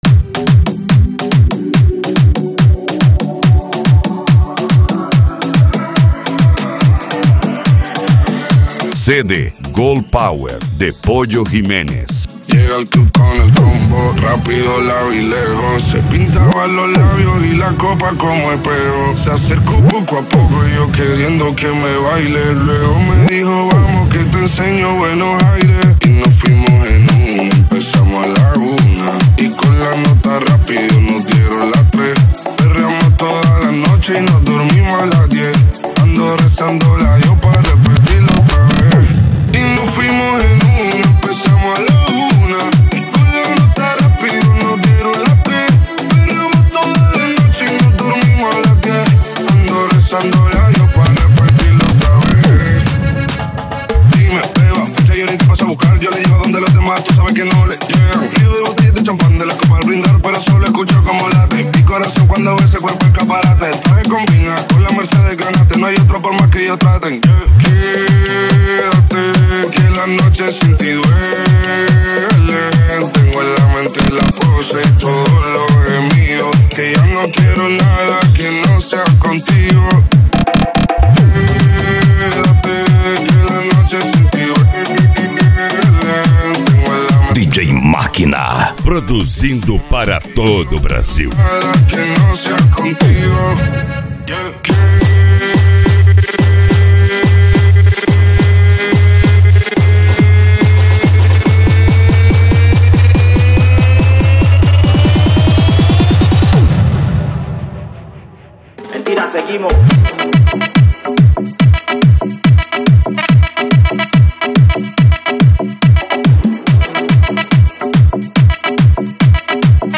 Cumbia
Reggae
Reggaeton